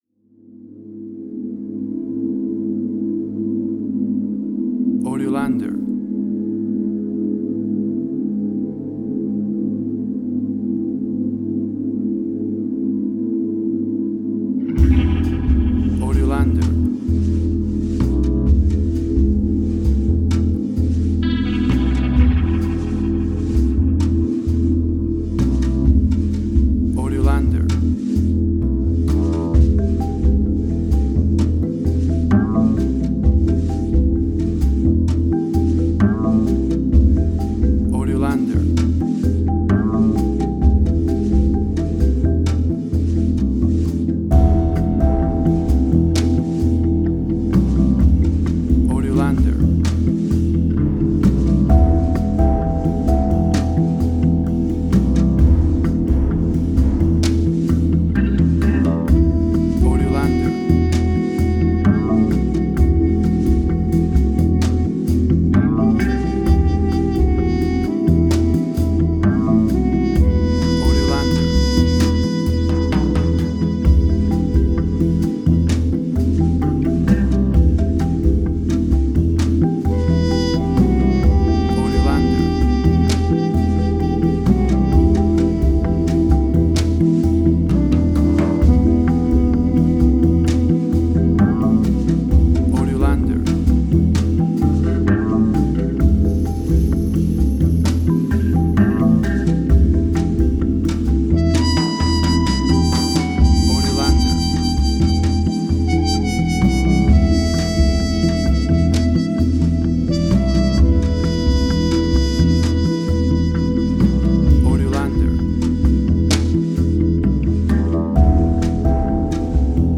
Modern Film Noir.
Tempo (BPM): 65